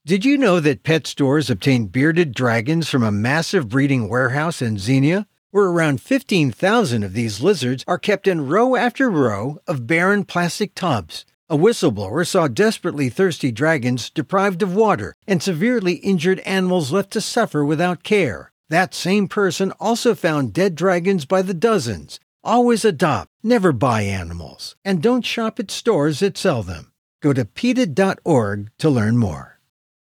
Instructions for Downloading This Radio PSA Audio File
reptilesbymack_radioad_option2_peta.mp3